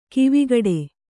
♪ kivigaḍe